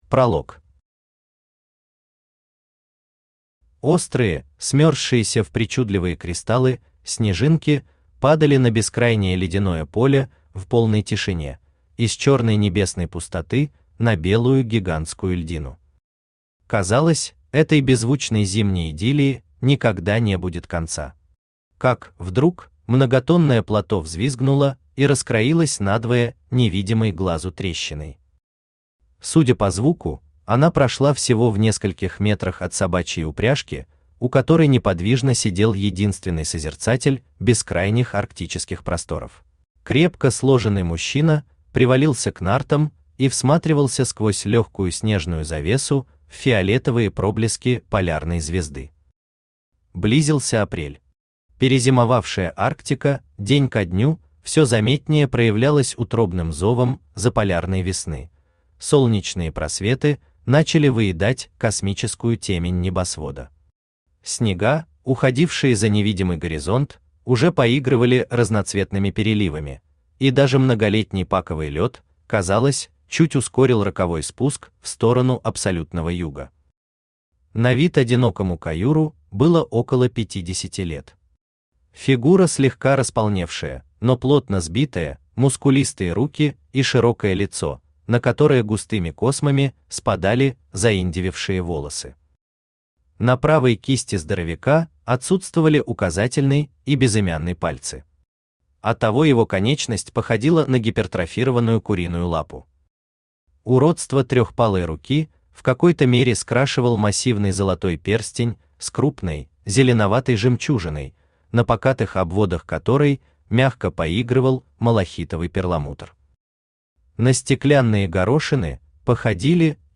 Аудиокнига Абсолютный дрейф | Библиотека аудиокниг
Aудиокнига Абсолютный дрейф Автор Роман Николаевич Зимьянин Читает аудиокнигу Авточтец ЛитРес.